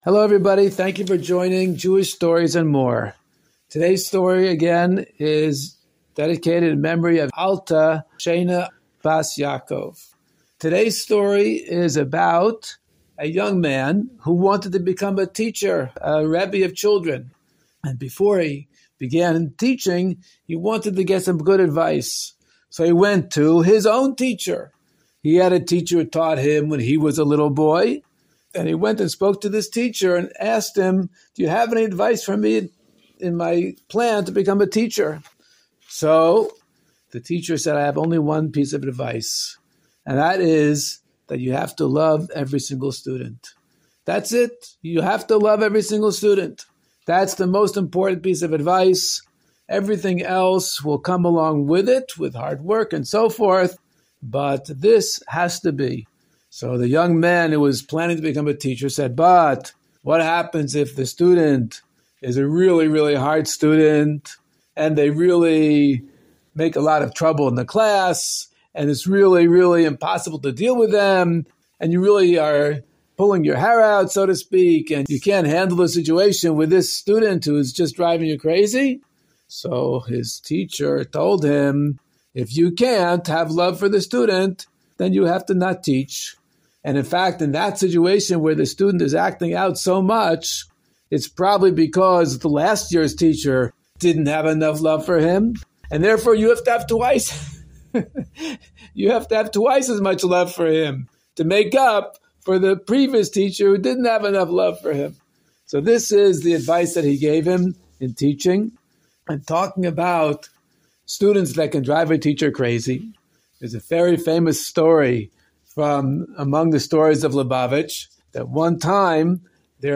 Story time for kids